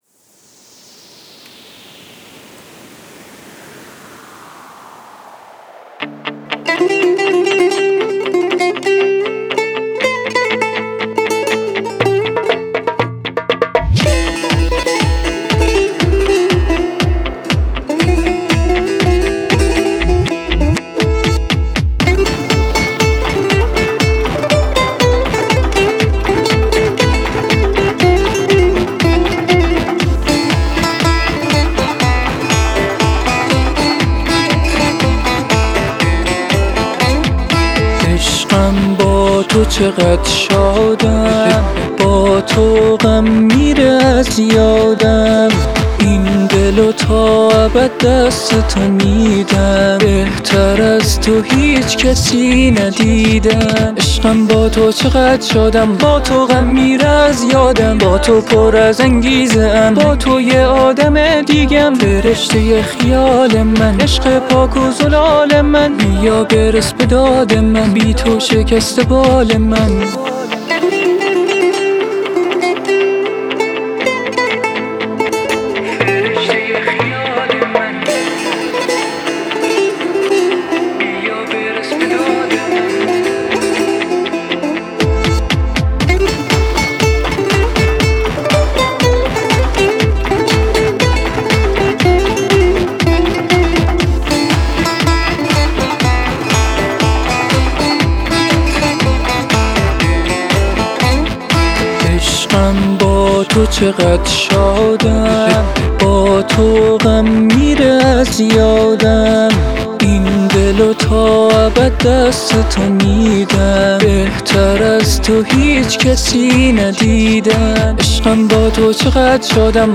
آهنگ غمگین